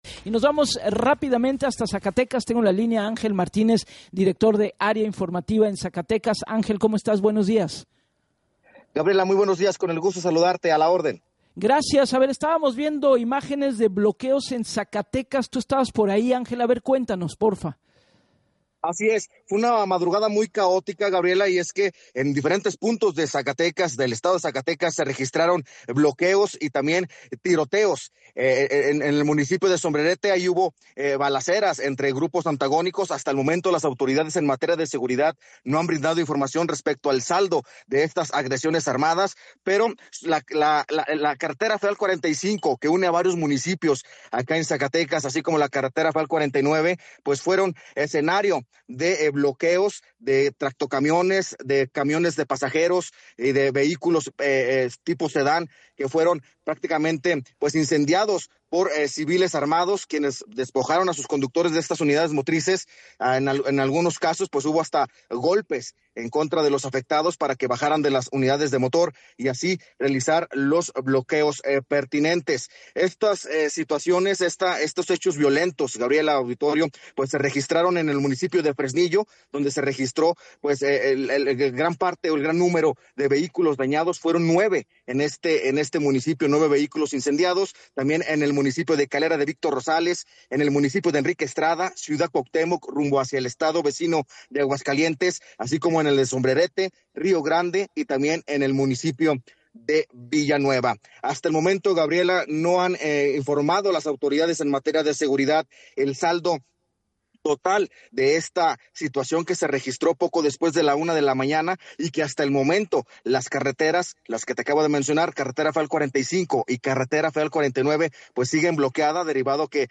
en entrevista para Así las Cosas con Gabriela Warkentin.